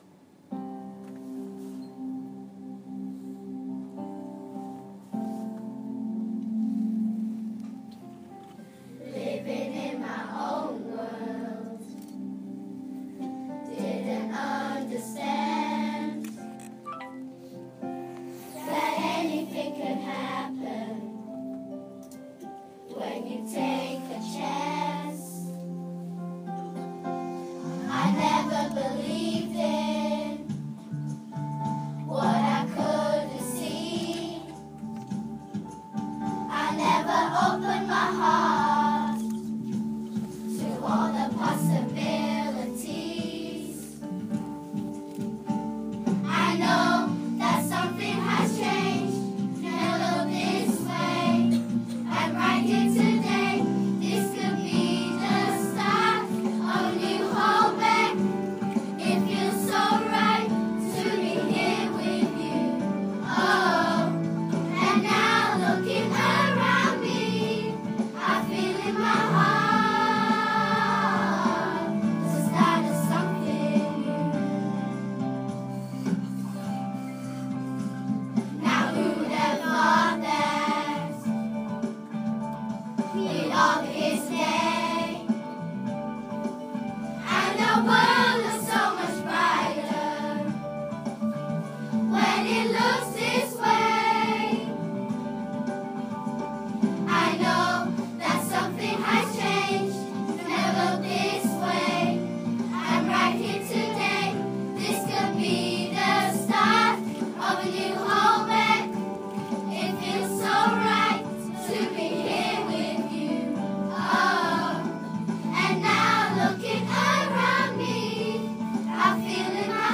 Sung by children from Ingram Road Primary School to mark the completion of new council housing in Holbeck, Leeds